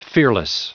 Prononciation du mot fearless en anglais (fichier audio)
Prononciation du mot : fearless